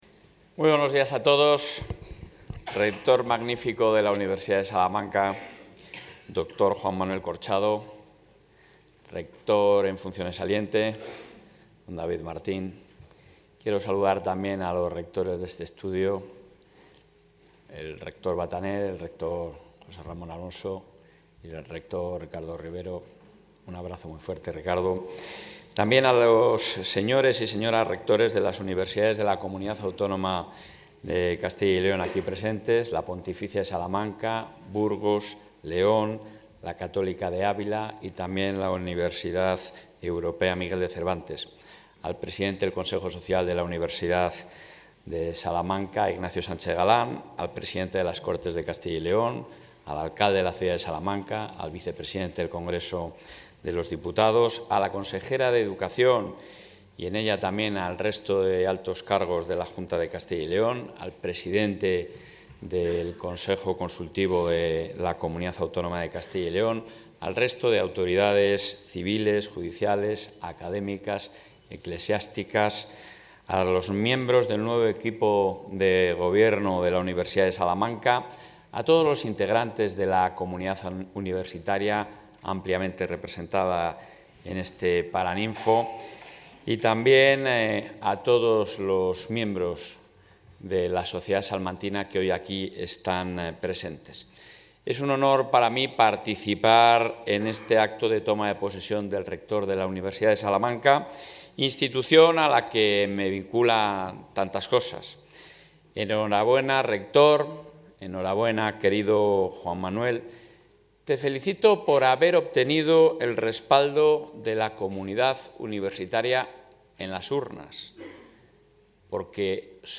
Intervención del presidente de la Junta.
El presidente del Ejecutivo autonómico, Alfonso Fernández Mañueco, ha participado en el acto de toma de posesión del rector de la Universidad de Salamanca, Juan Manuel Corchado, donde ha ensalzado la función que realizan las universidades como espacios de enseñanza, ciencia e investigación en los que tiene cabida la reflexión y el análisis crítico, y que se deben preservar de intereses partidistas.